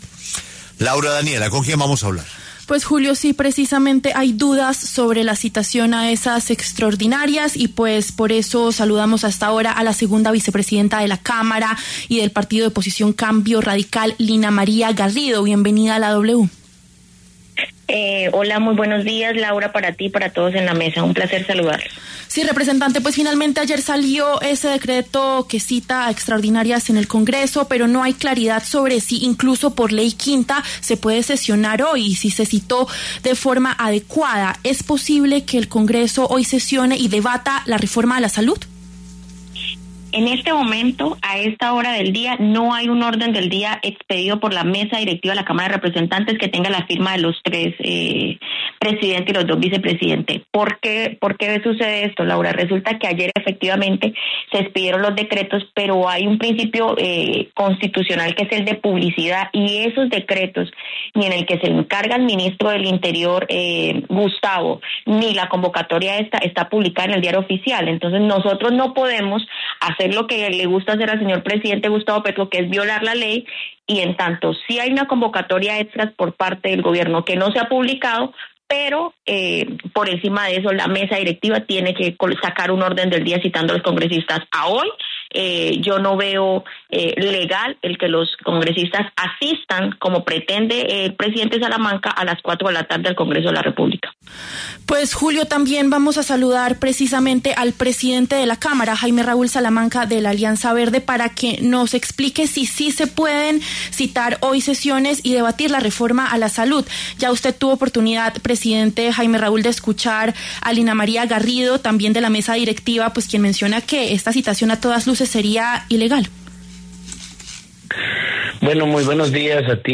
El presidente de la Cámara, Jaime Raúl Salamanca, y la segunda vicepresidente de esa corporación, Lina María Garrido, debatieron en los micrófonos de La W. La representante de oposición aseguró que la citación a extras es ilegal y que no será firmada por los vicepresidentes.